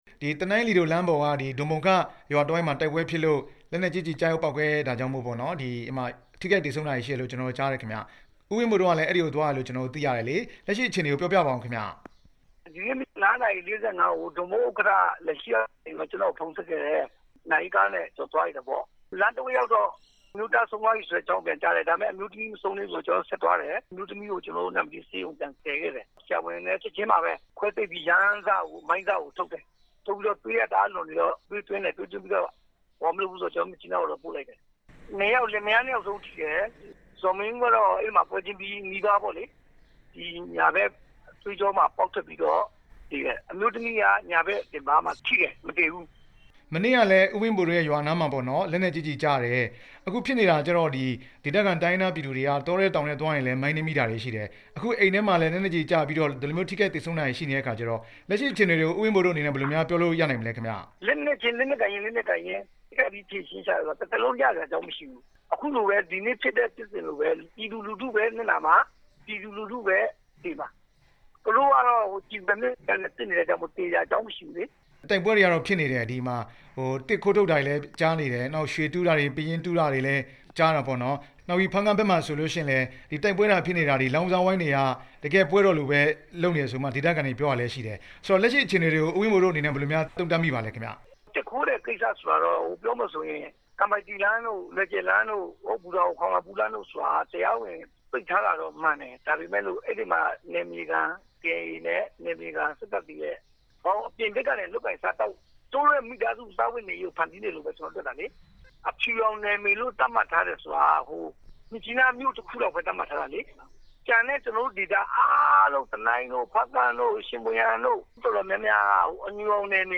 လီဒိုလမ်းပေါ်မှာ တိုက်ပွဲဖြစ်တဲ့အကြောင်း မေးမြန်းချက်